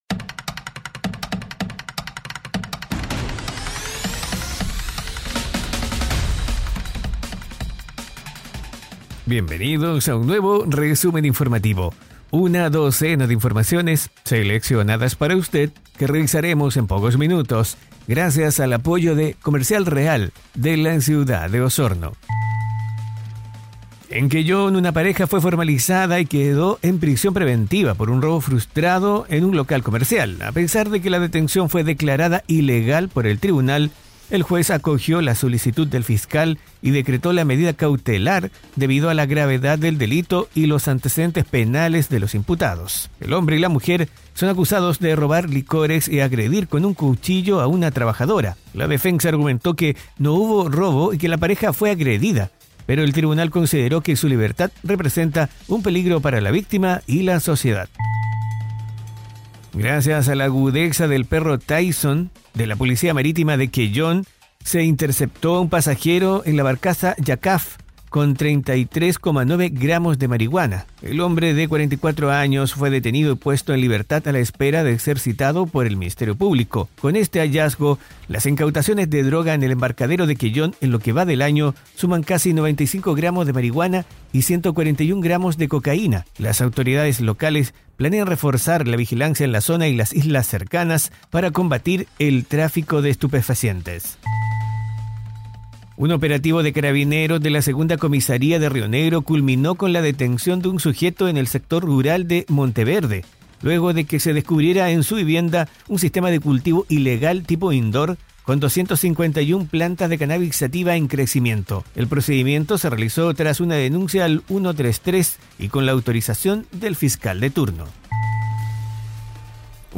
🎙 ¡Tu resumen informativo en podcast está aquí! 🗞 🚀 Conoce las noticias más relevantes de la Región de Los Lagos de forma ágil y breve.